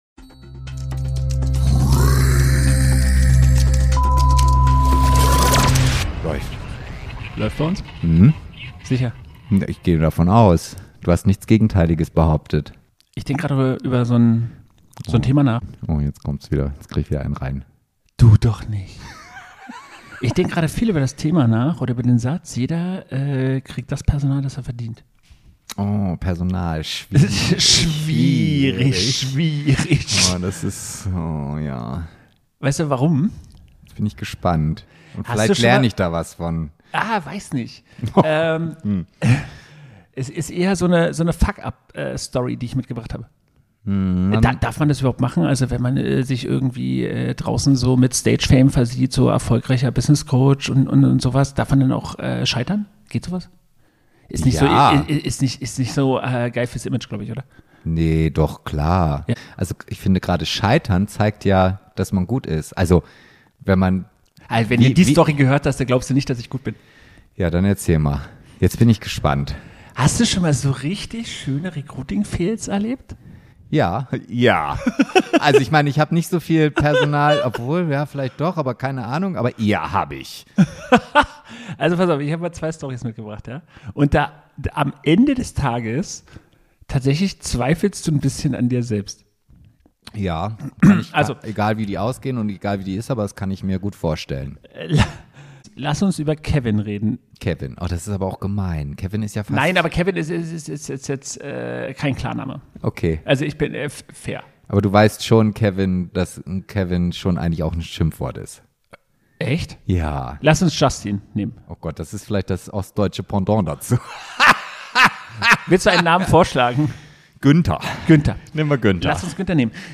In dieser Folge sprechen wir offen über echte Recruiting-Fails, absurde Bewerbermomente und die Frage: Wie viel Empathie ist zu viel – und wann ist Schluss mit nett? Eine ehrliche, laute und manchmal bittere Folge über Personal, Fehlentscheidungen und das Lernen aus Chaos.